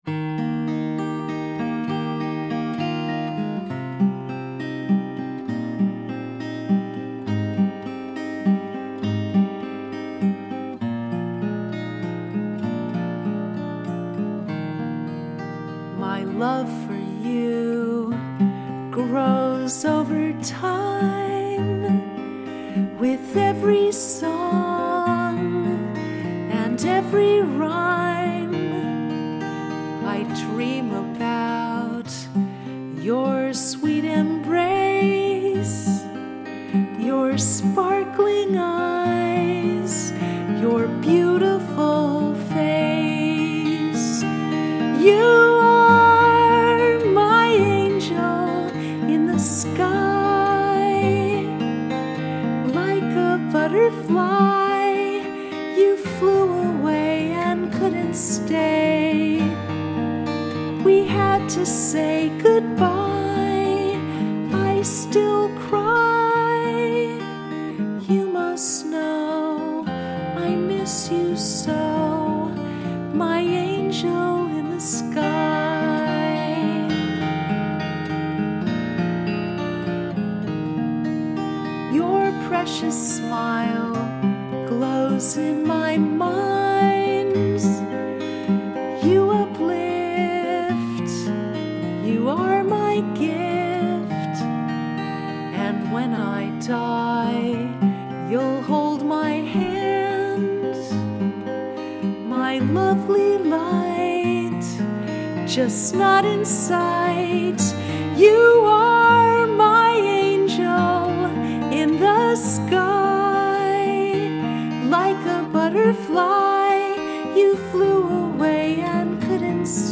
Angel in the Sky Arrangement 1-25-18